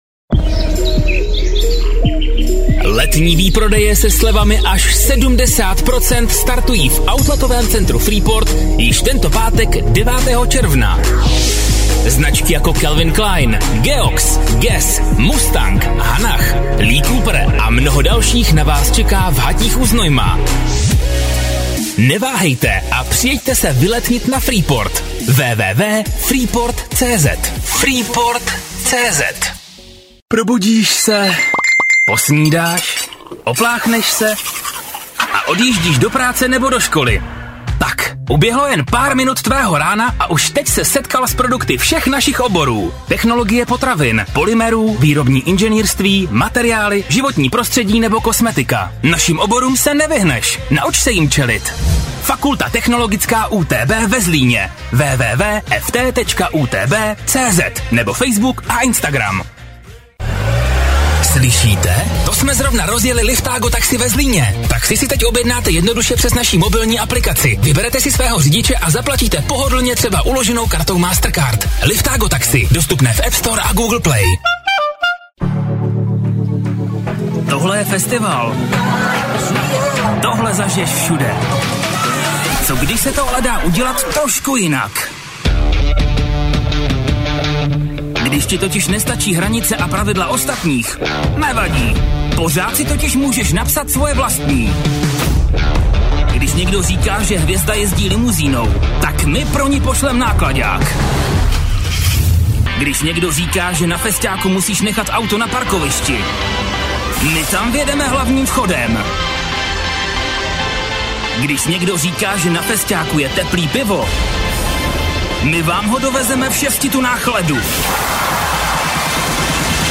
Umím: Voiceover, Ostatní audio
Namluvil jsem již tisíce různých spotů a videí - voiceoverů (TV, rádio, internet, aj.).